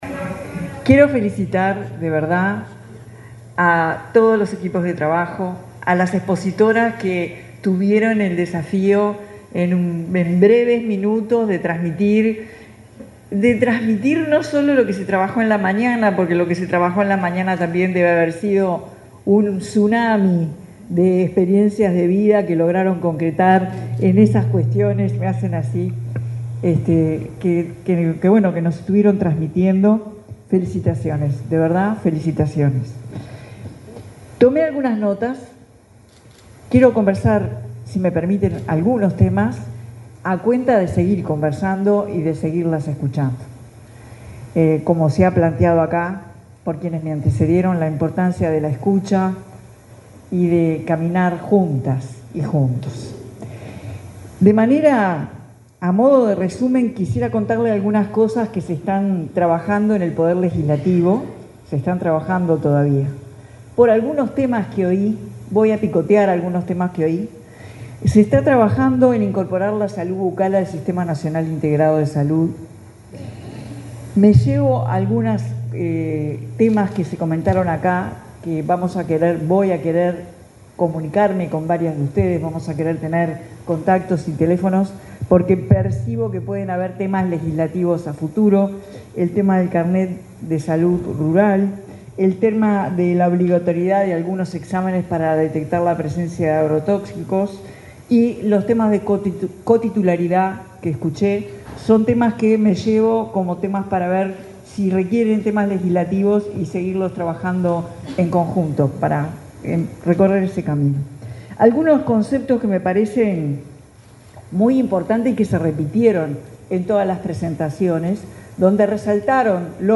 Palabras de la presidenta en ejercicio, Carolina Cosse 15/10/2025 Compartir Facebook X Copiar enlace WhatsApp LinkedIn La presidenta de la República en ejercicio, Carolina Cosse, se expresó, durante el cierre del encuentro nacional por los 10 años del Espacio de Diálogo de Mujeres Rurales, realizado este miércoles 15 en Florida.